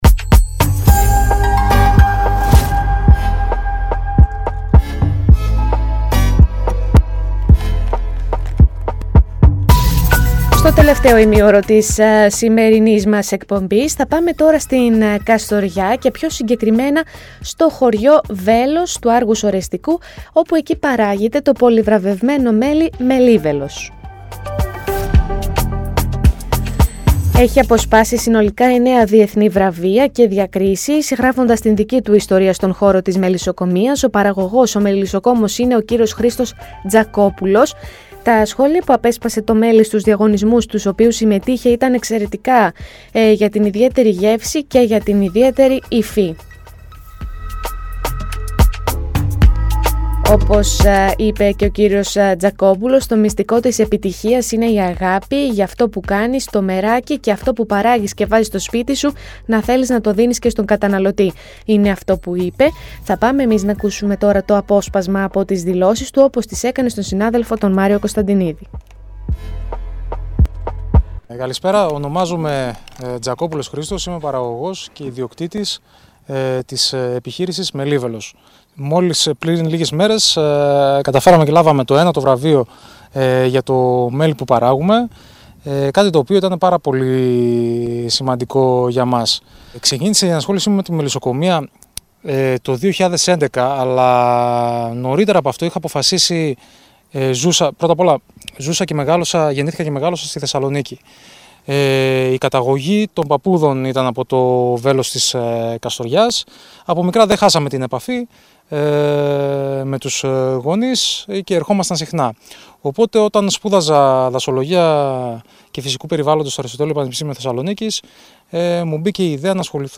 Πρωϊνό ενημερωτικό “κους-κους” με διαφορετική ματιά στην ενημέρωση της περιοχής της Φλώρινας και της Δυτικής Μακεδονίας, πάντα με την επικαιρότητα στο πιάτο σας.